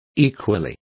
Complete with pronunciation of the translation of equally.